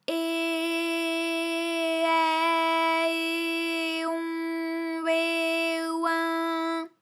ALYS-DB-001-FRA - First, previously private, UTAU French vocal library of ALYS
eh_eh_ai_eh_on_eh_oin.wav